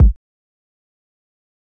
Waka Kick - 1 (1).wav